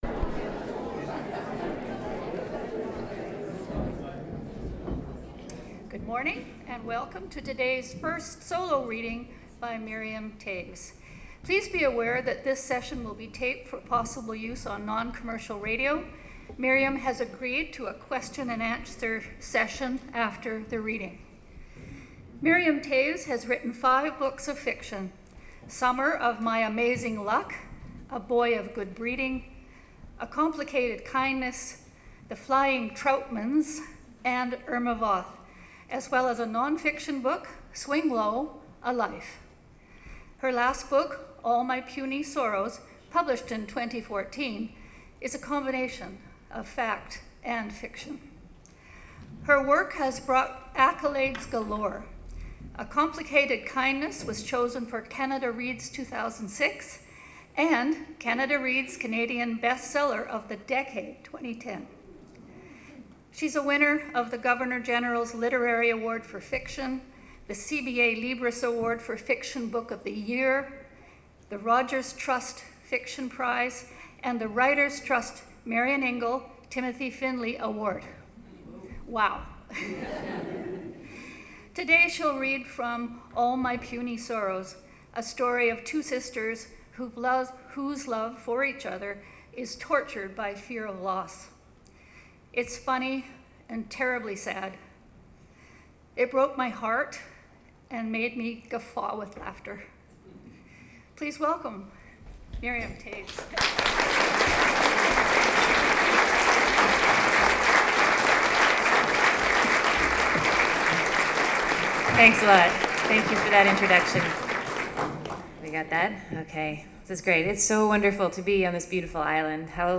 Miriam Toews reading and talking about her life